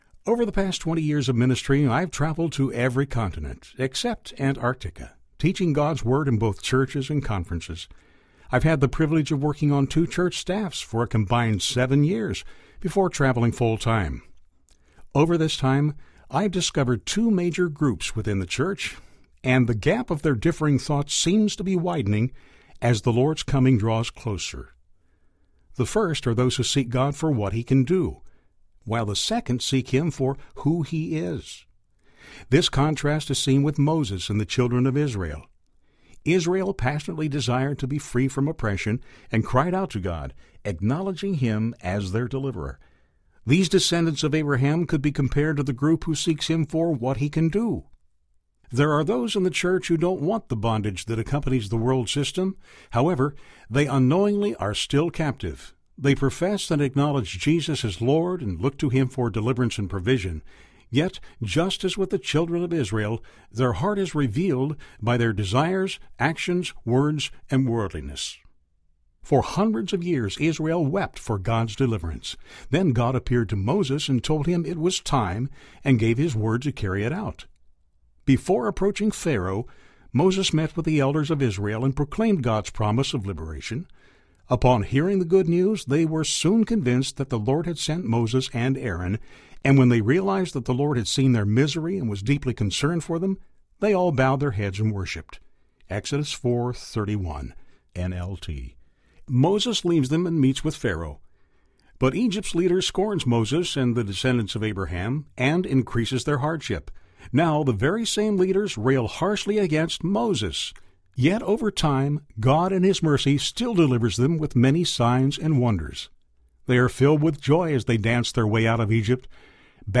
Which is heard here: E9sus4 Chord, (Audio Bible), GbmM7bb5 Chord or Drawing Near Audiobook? Drawing Near Audiobook